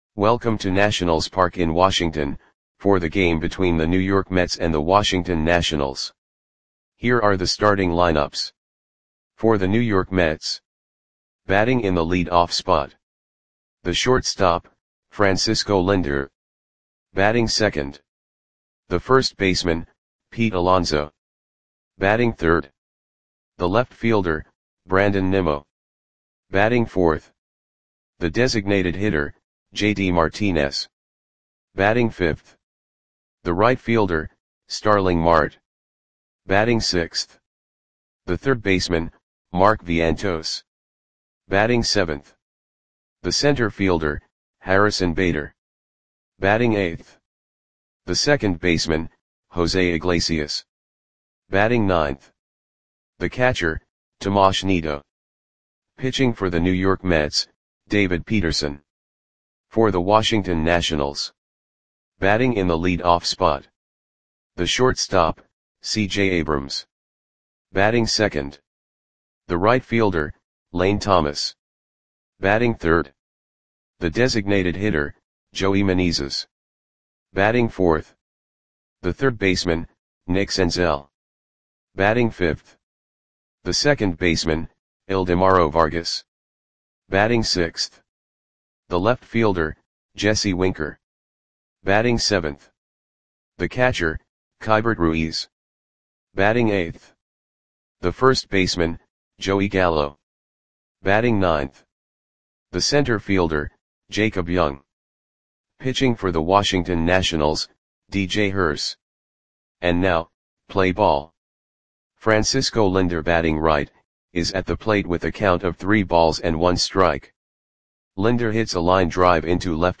Audio Play-by-Play for Washington Nationals on June 4, 2024
Click the button below to listen to the audio play-by-play.